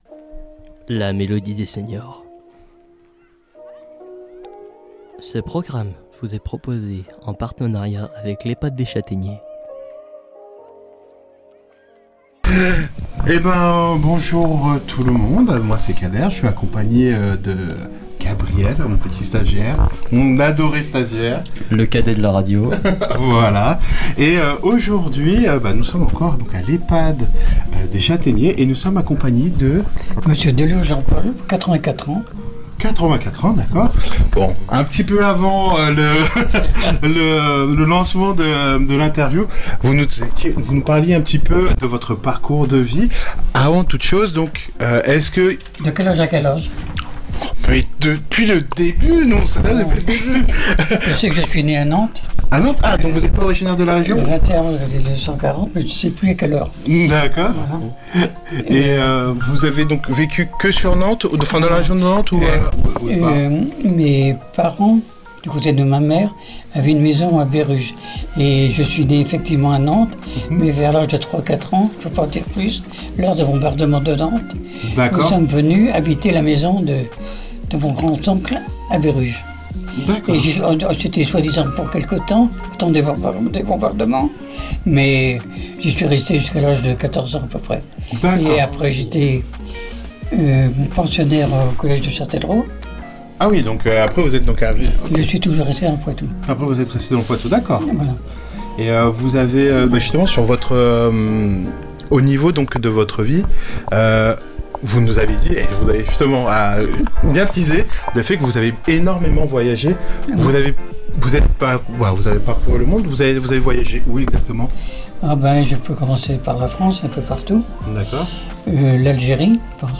Une rencontre en musique des habitants de l’ehpad des chataigners.